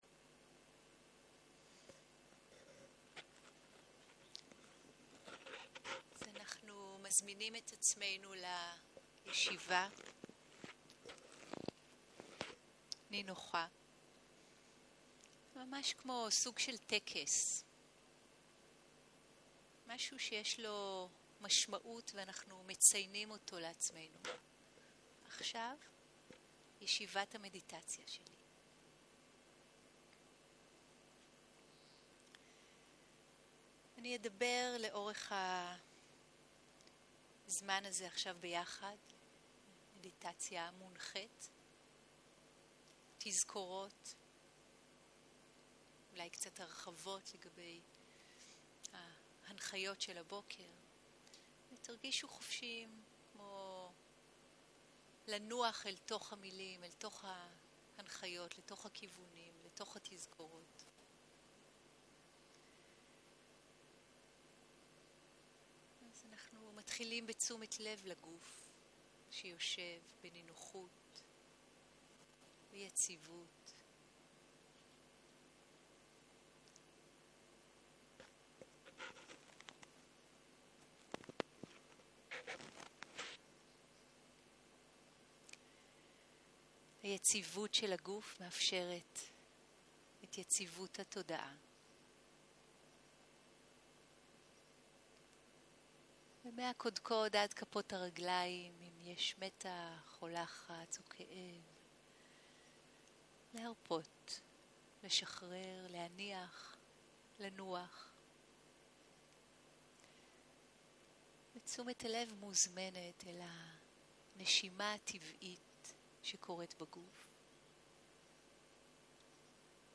20.04.2023 - יום 2 - צהרים - מדיטציה מונחית - הקלטה 2
Dharma type: Guided meditation שפת ההקלטה